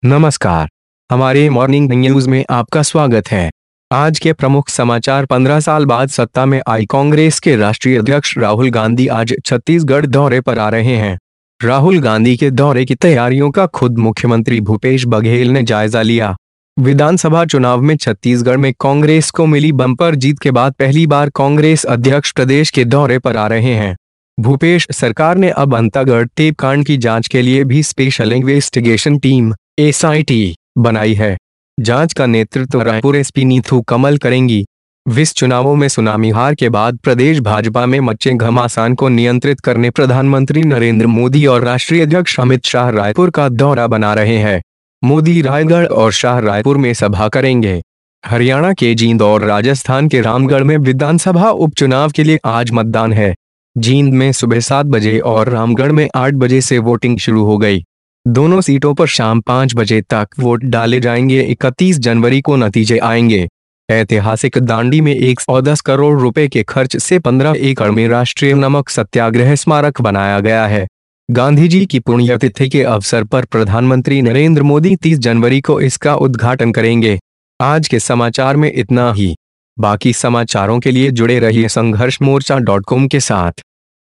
28 जनवरी Morning news:आज राहुल गांधी प्रदेश के दौरे पर आएंगे,अंतागढ़ टेप कांड के लिए SIT का गठन